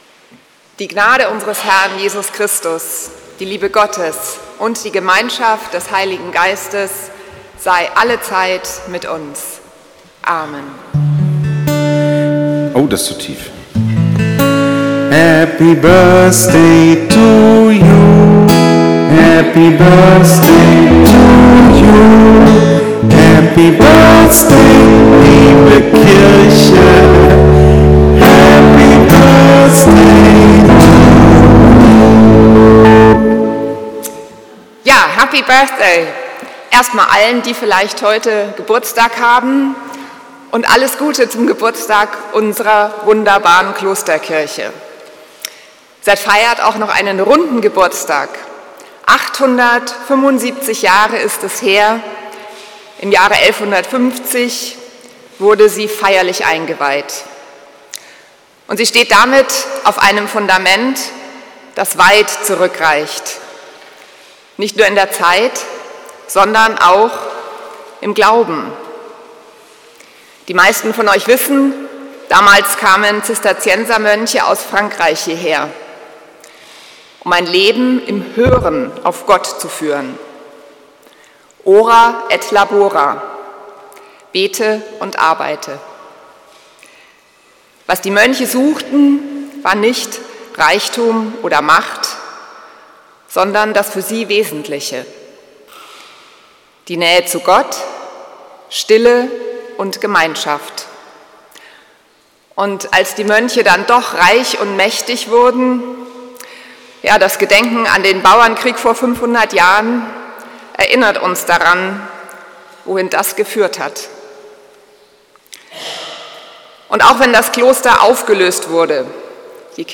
Klosterkirche Volkenroda, 29. Juni 2025